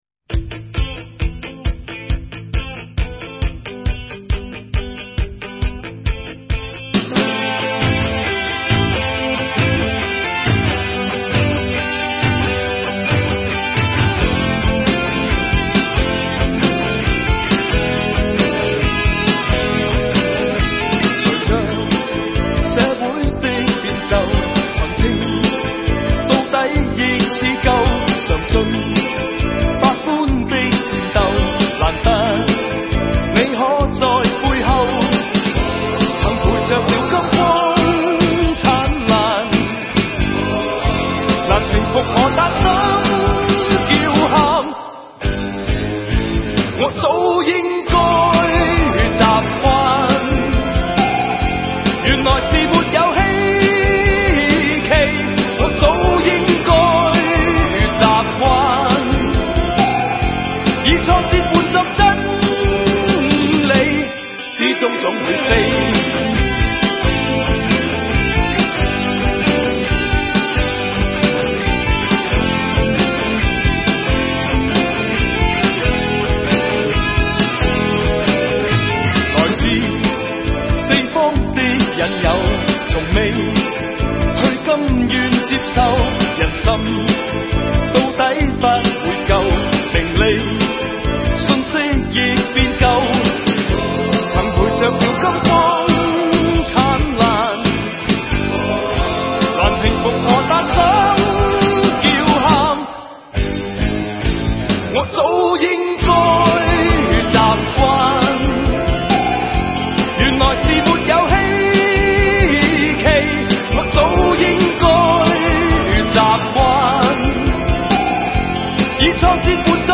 粤语专辑